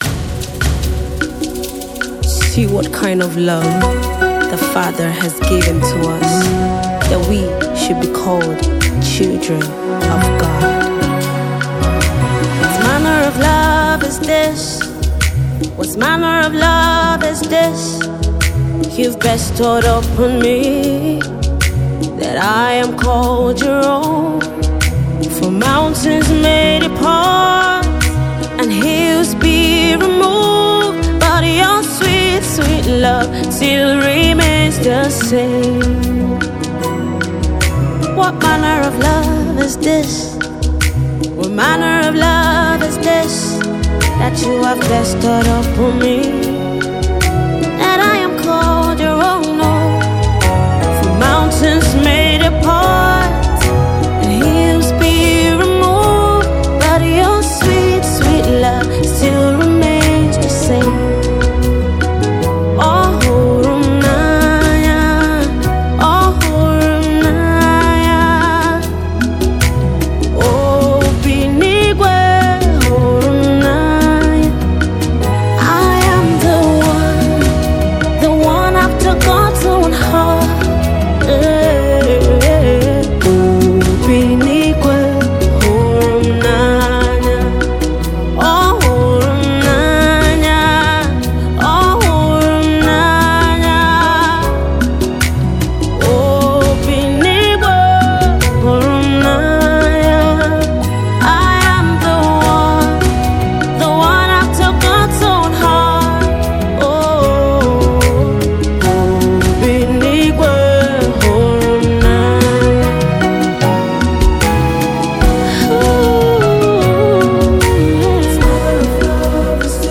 Igbo Gospel Music
soul-stirring anthem